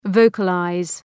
Προφορά
{‘vəʋkə,laız}